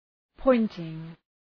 Προφορά
{‘pɔıntıŋ}